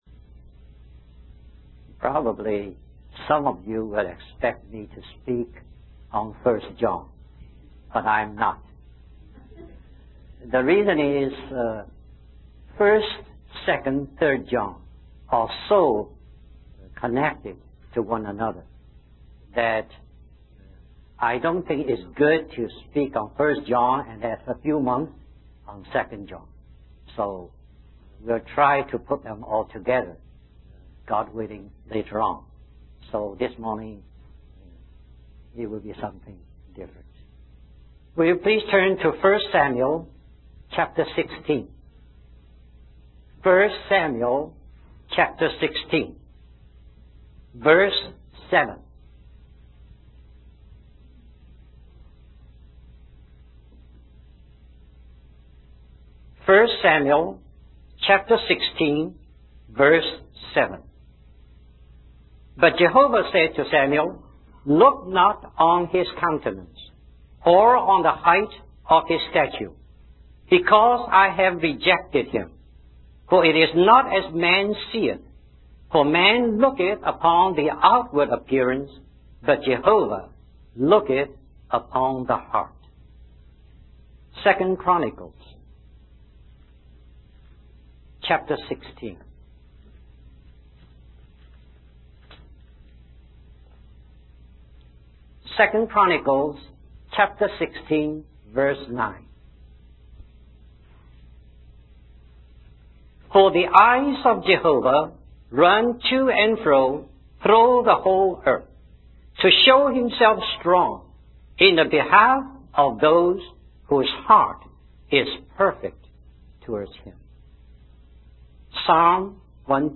In this sermon, the speaker shares a personal experience of visiting a chapel in the Philippines and seeing nuns praying and meditating. He uses this experience to emphasize the importance of presenting our bodies as living sacrifices to God and allowing Him to transform our minds and viewpoints.